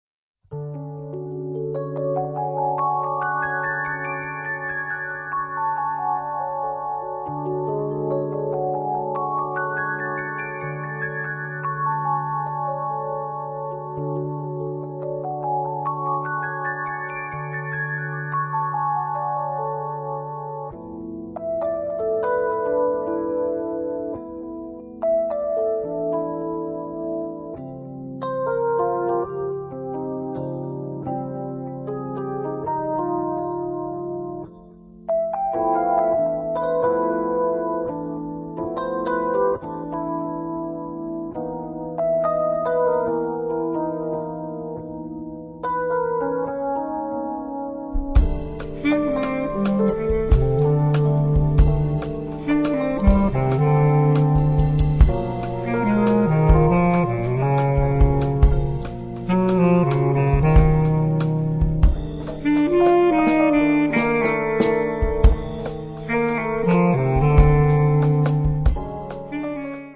batteria
sax
piano